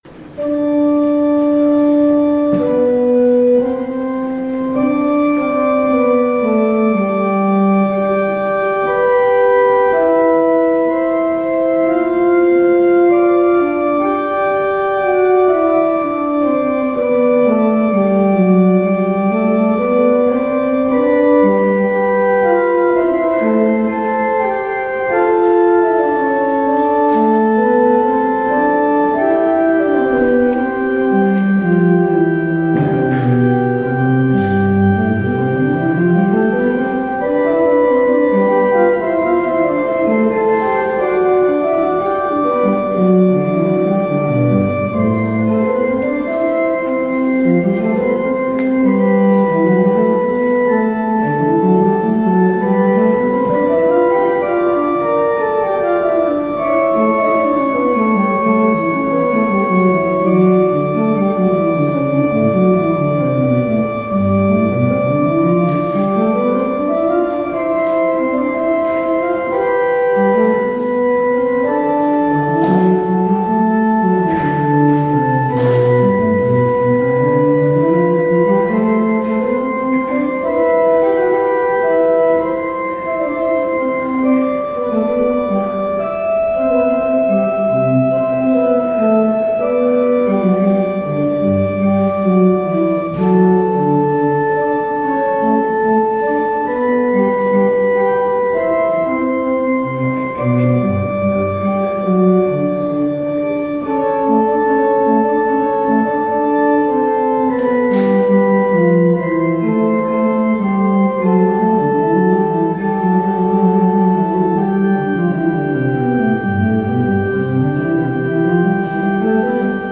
Audio recording of the 10am hybrid/streamed service